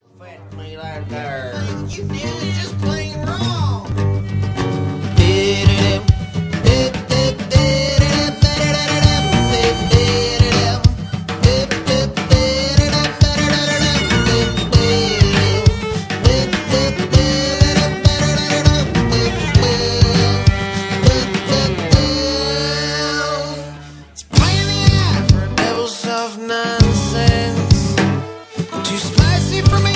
viola